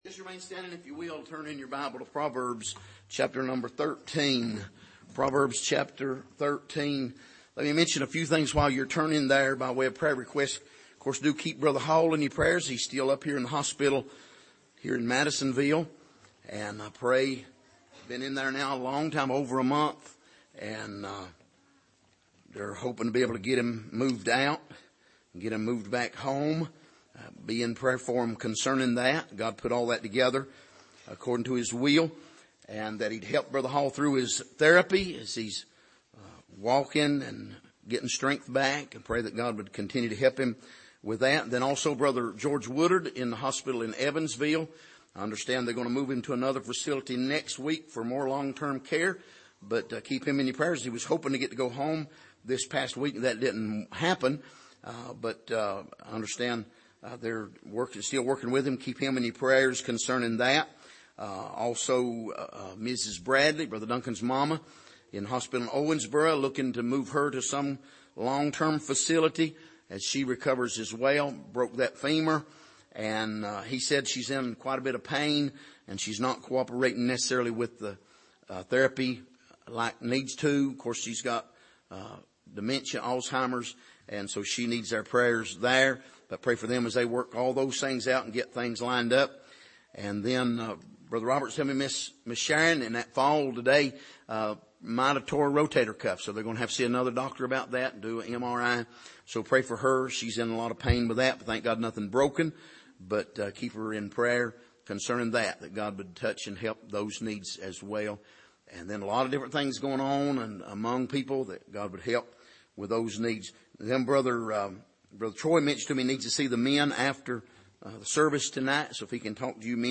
Passage: Proverbs 13:1-9 Service: Sunday Morning